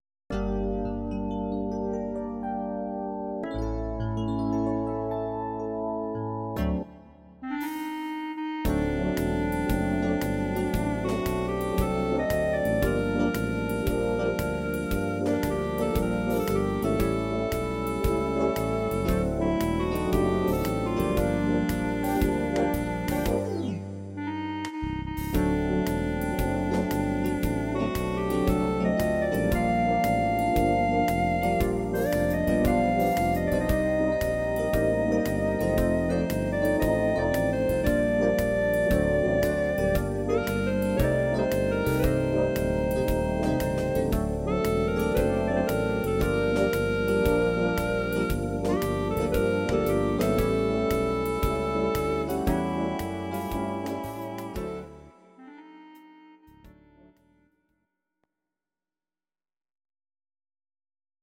These are MP3 versions of our MIDI file catalogue.
Please note: no vocals and no karaoke included.
instr. Klarinette